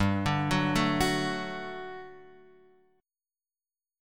GM7sus2 chord {3 5 4 2 x 2} chord